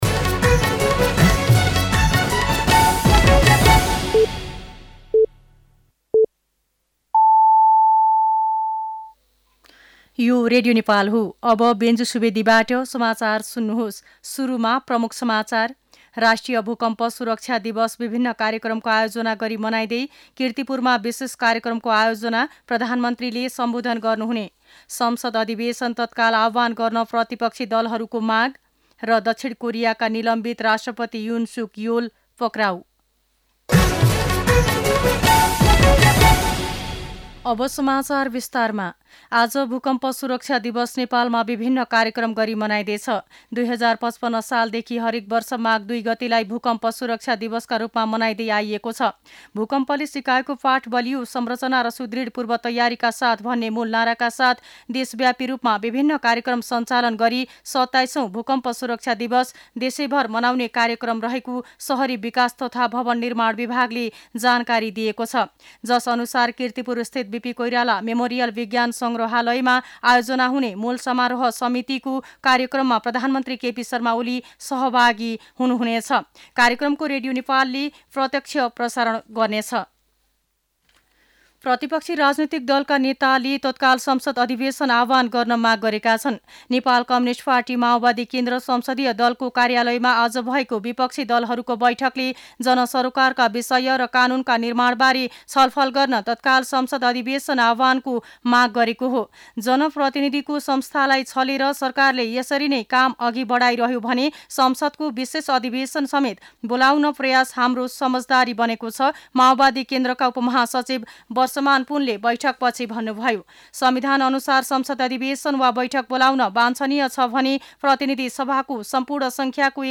दिउँसो ३ बजेको नेपाली समाचार : ३ माघ , २०८१
3-pm-Nepali-News-10-02.mp3